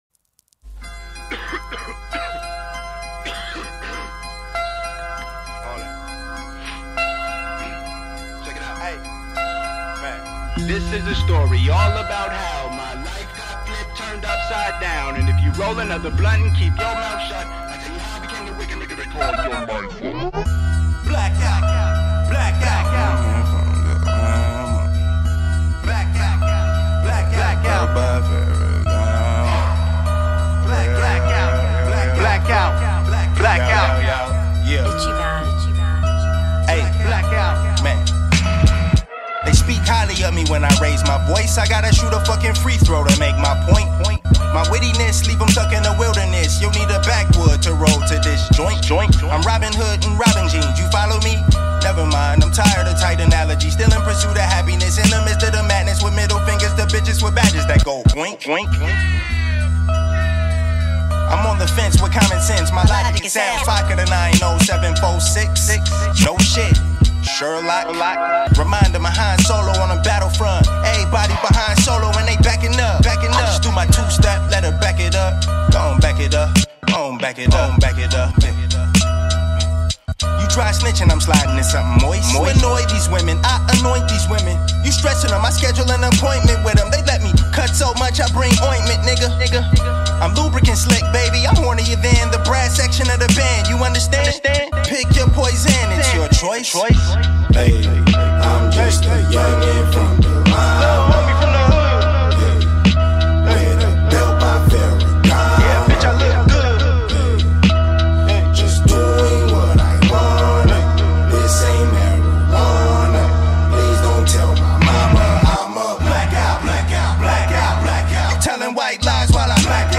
all while rapping over dark beats.